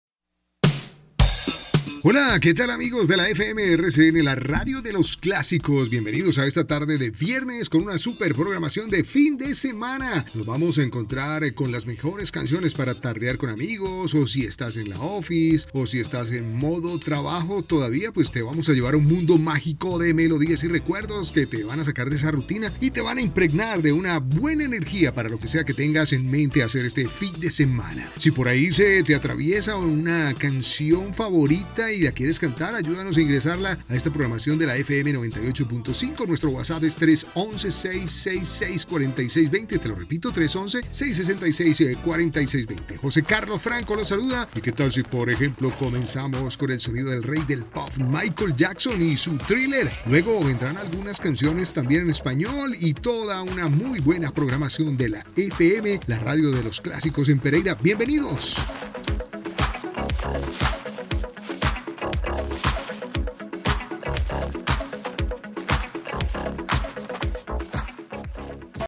Micrófono Scarlett CM 25 Interfaz Focusrite Scarlett solo 4 Audífonos Scarlet SM 450 Adobe Audition
kolumbianisch
Sprechprobe: Industrie (Muttersprache):
My voice range is from 25 to 60 years old. My voice is warm, mature, energetic, happy, brassy, narrator tips.